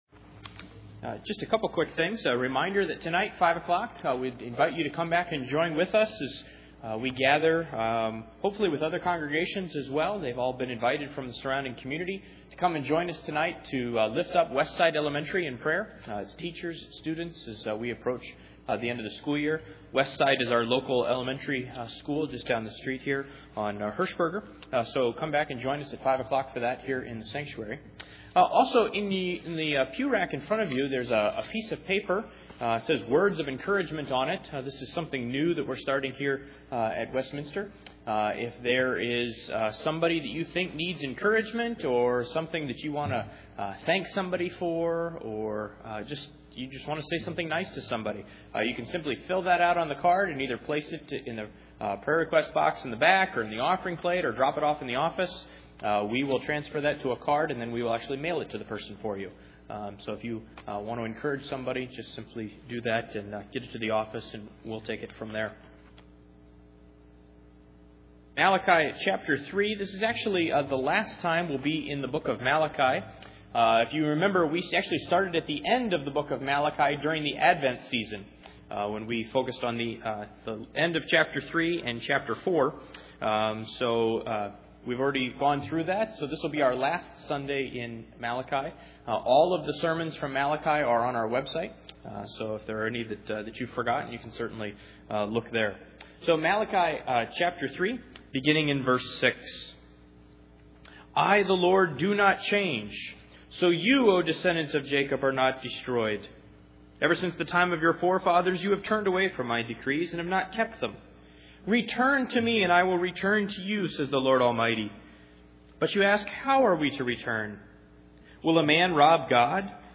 Malachi 3:6-12 Service Type: Sunday Morning The Israelites are withholding their tithes and offerings because they doubt it's worth it.